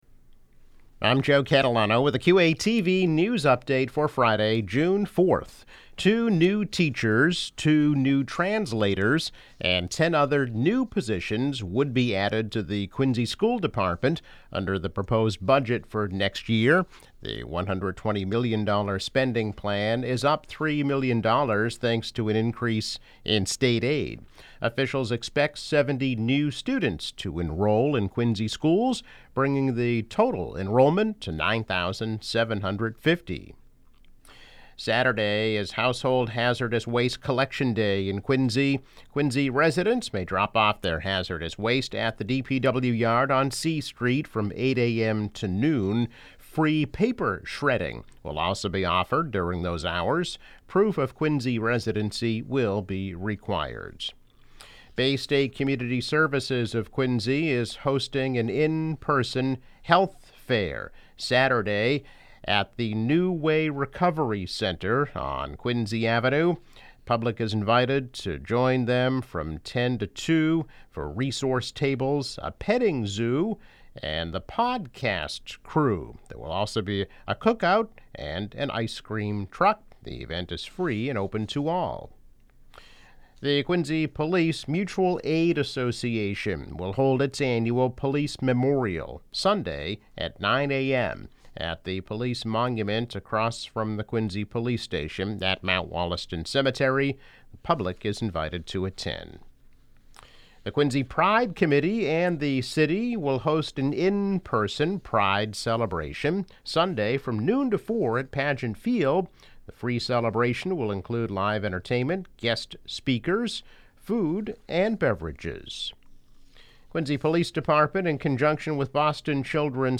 News Update - June 4, 2021